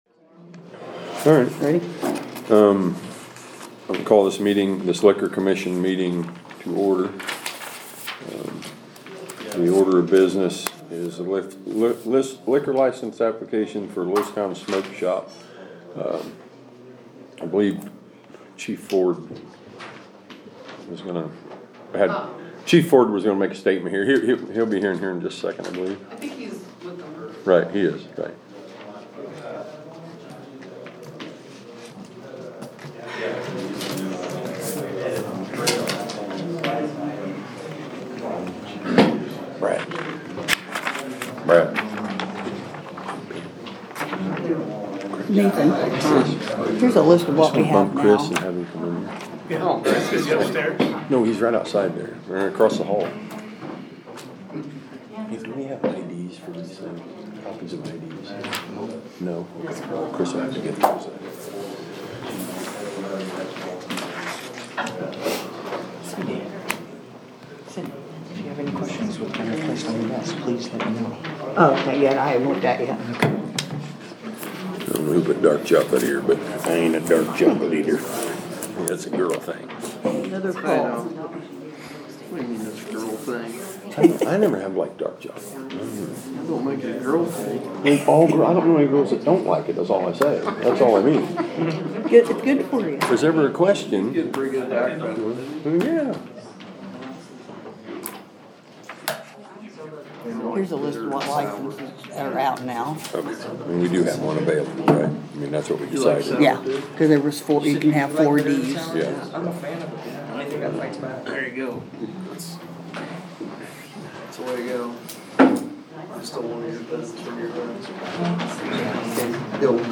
April 8th, 2025 City Council Meeting Audio Updated